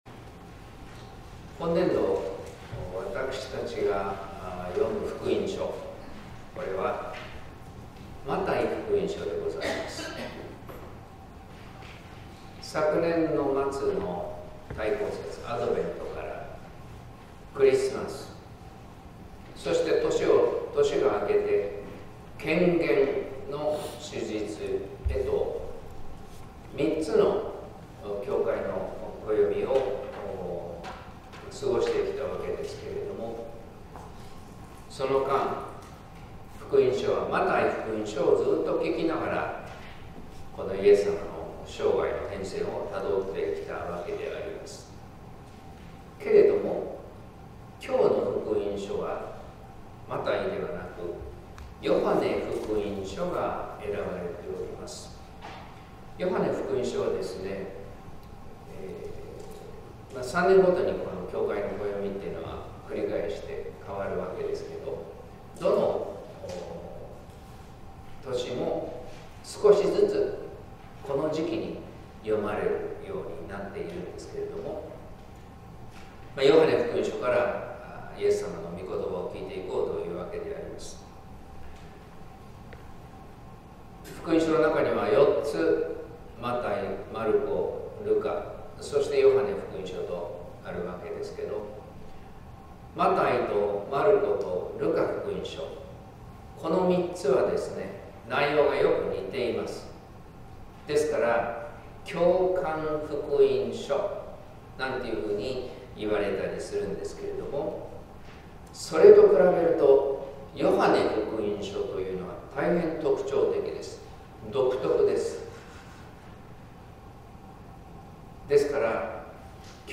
説教「罪の赦しと神の子羊」（音声版） | 日本福音ルーテル市ヶ谷教会